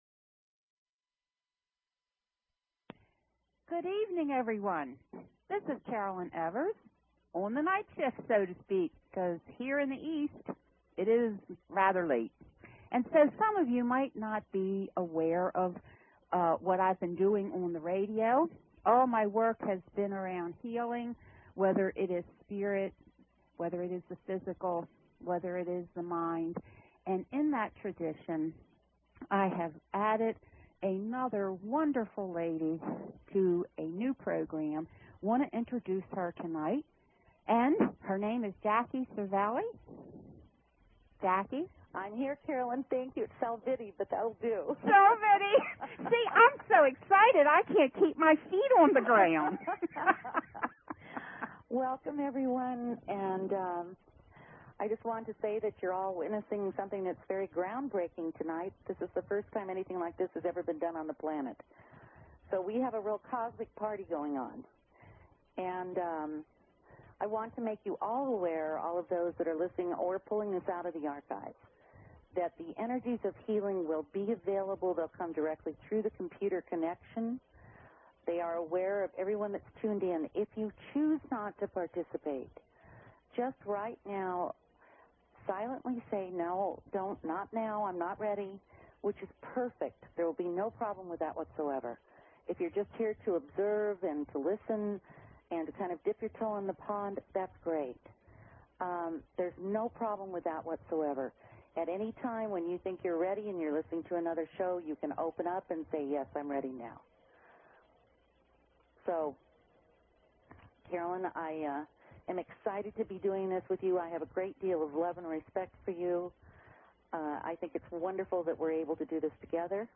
Debut of ET Healing talk radio show, September 14, 2006